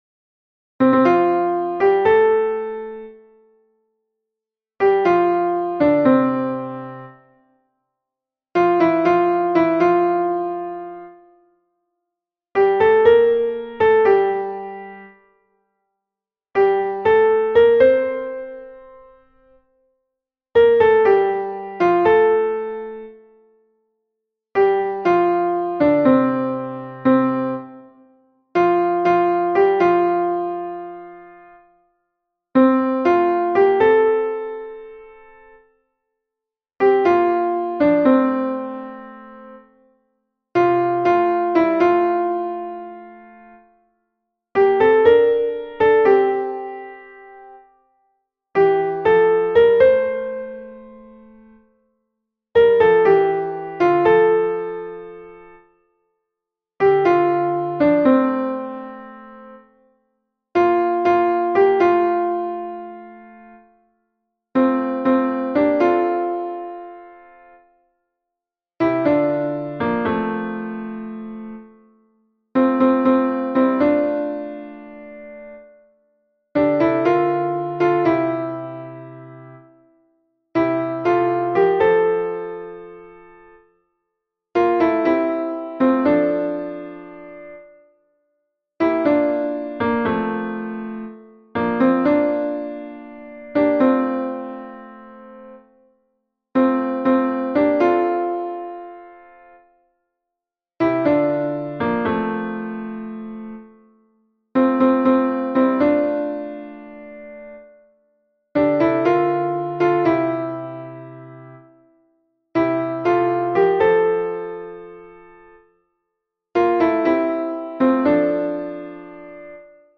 MP3 version piano
Alto Piano